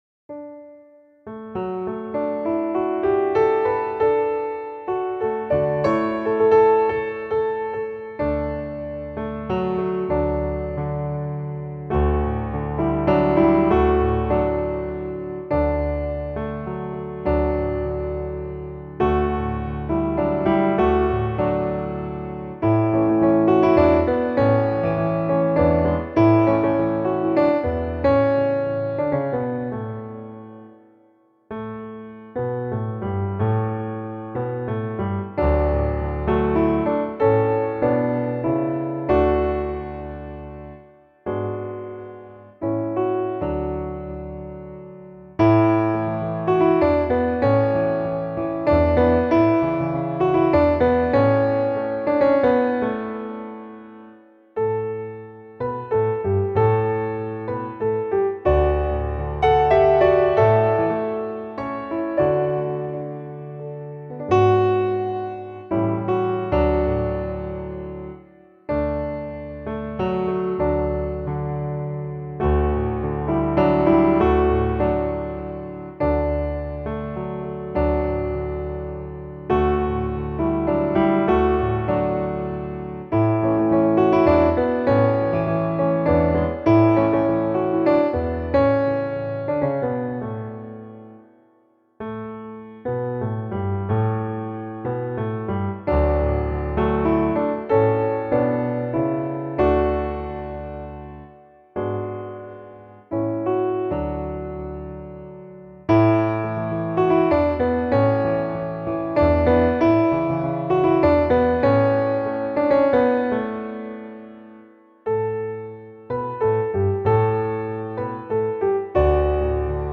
Aby ułatwić przygotowanie się do nagrania, poniżej prezentujemy podkłady muzyczne, w trzech wersjach.